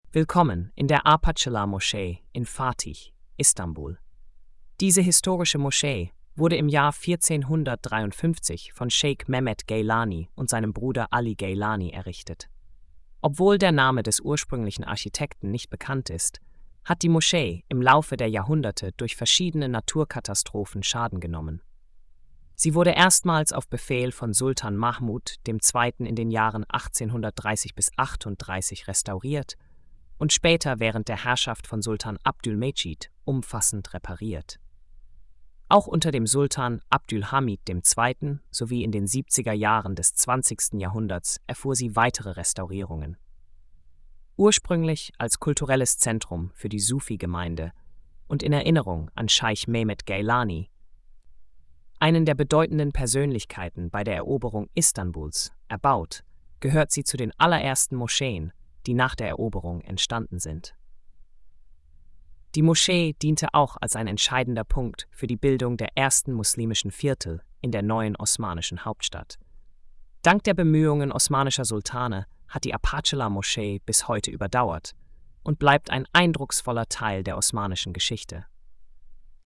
Audio Erzählung